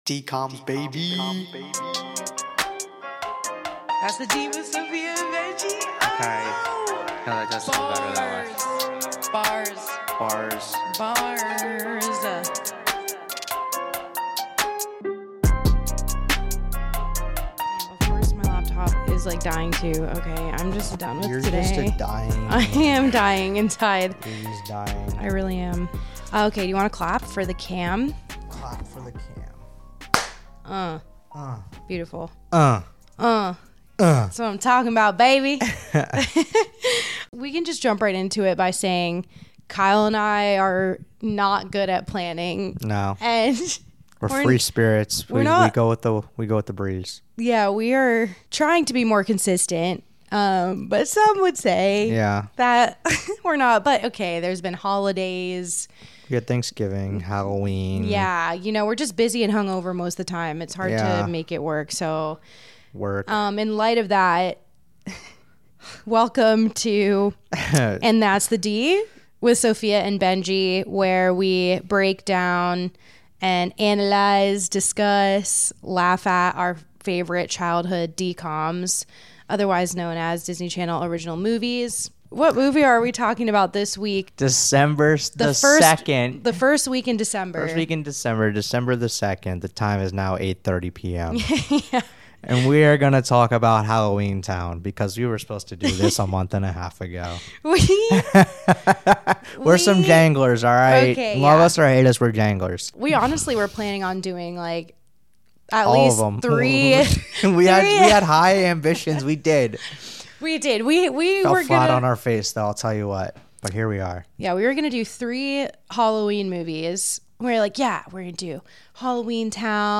A Disney Channel Original Movie comedy companion podcast.